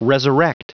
Prononciation du mot resurrect en anglais (fichier audio)
Prononciation du mot : resurrect